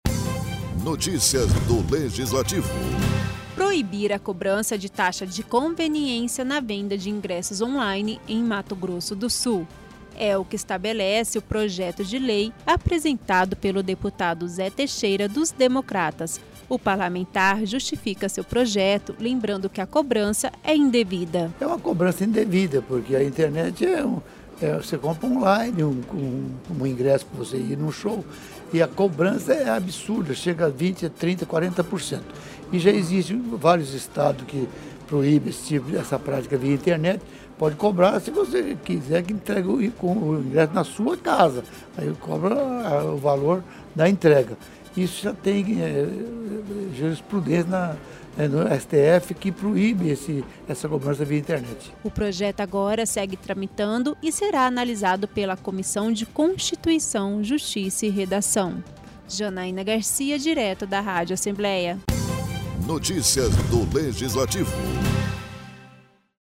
Projeto de lei que proíbe a cobrança de taxa de conveniência na venda de ingressos via internet éapresentado pelo deputado Zé Teixeira (DEM) durante sessão ordinária desta terça-feira (9).